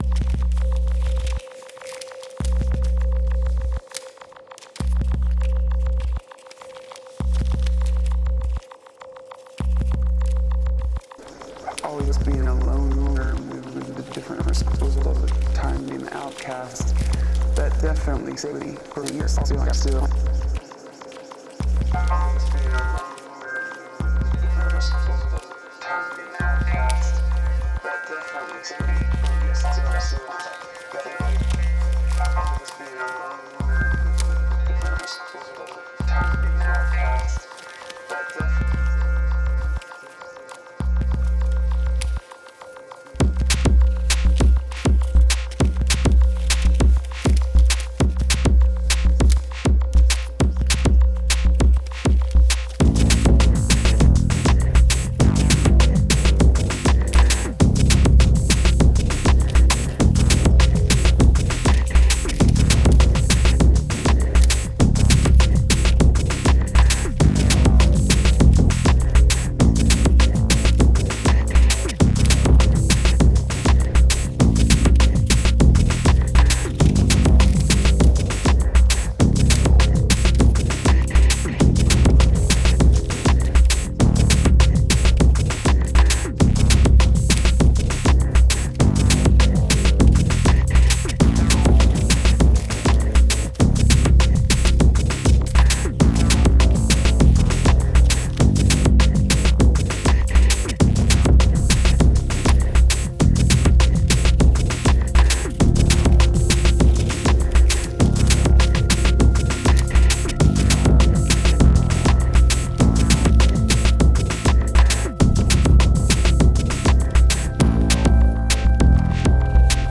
IDM